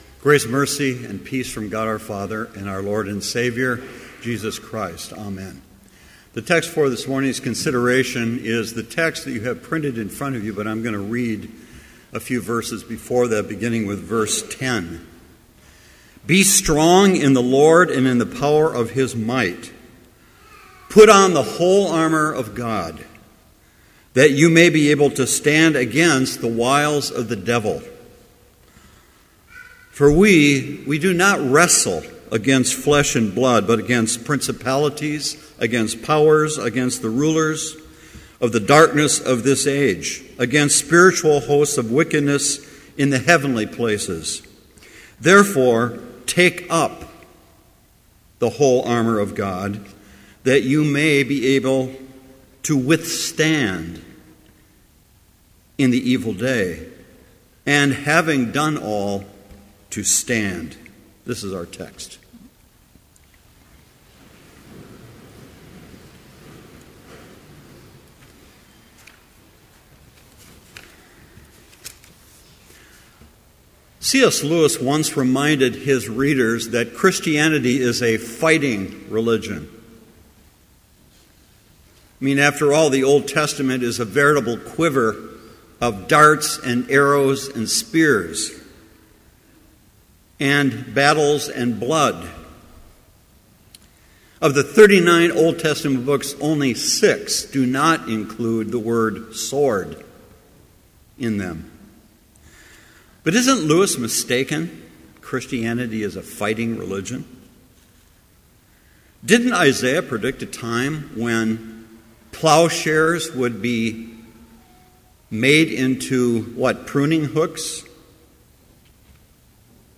Complete service audio for Chapel - March 26, 2014